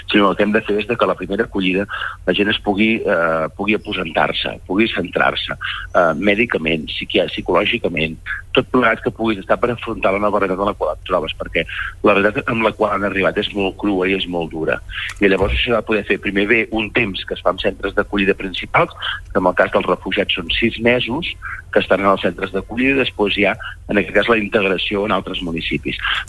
En una entrevista al Supermatí, David Saldoni, president de l’Associació Catalana de Municipis i alcalde de Sallent, ha repassat l’actualitat del conjunt dels municipis del territori català.